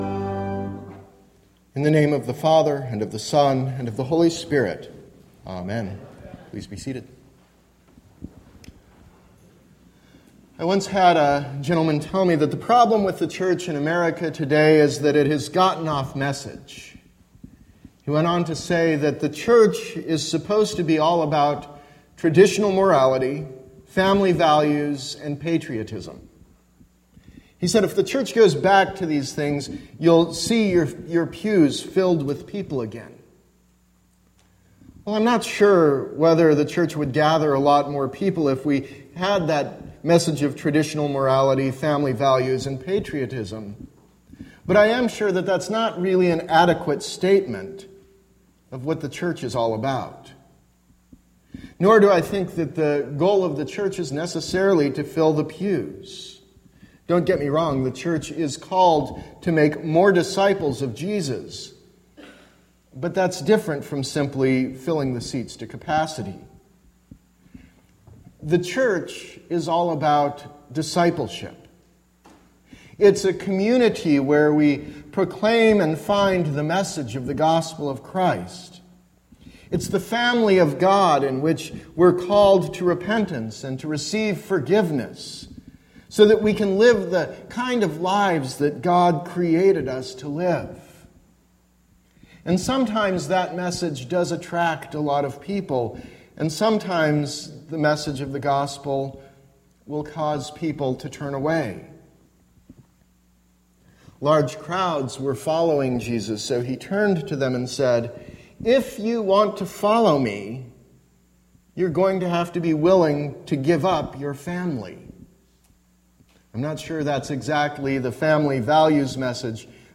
Sermon – September 4, 2016